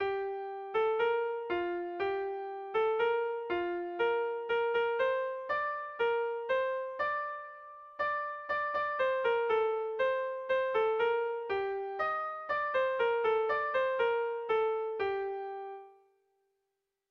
Irrizkoa
ABD